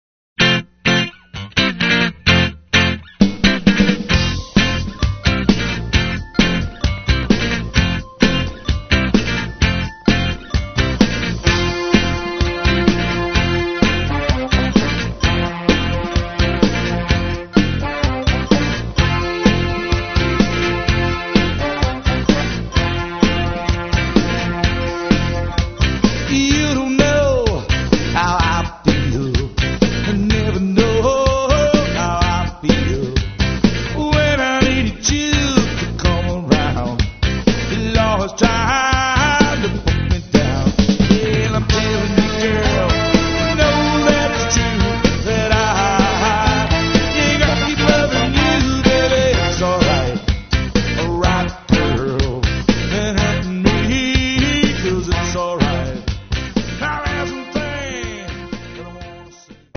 We recorded it at Round Table Recording Studio in Eagle, WI.